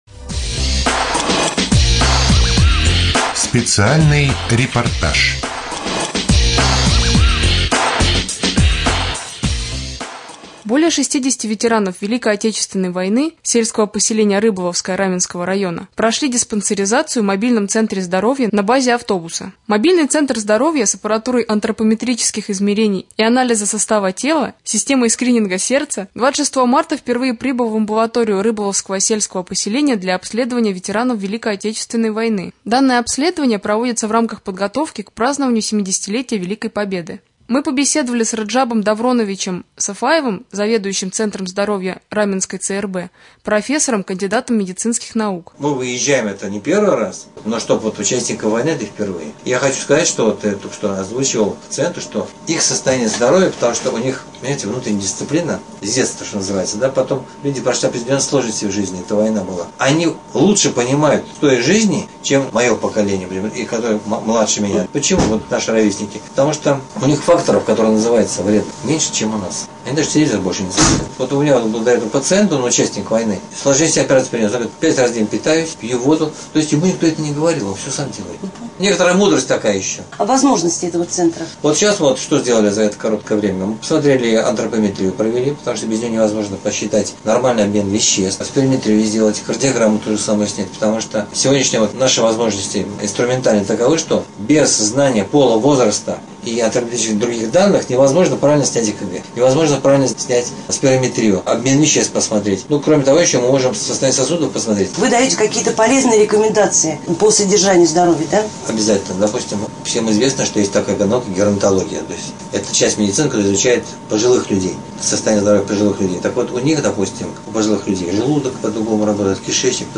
2.Рубрика «Специальный репортаж». Ветераны ВОВ с/п Рыболовское прошли диспансеризацию в мобильном центре здоровья.